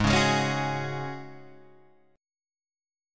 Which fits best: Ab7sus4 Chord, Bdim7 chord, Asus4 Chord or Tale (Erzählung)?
Ab7sus4 Chord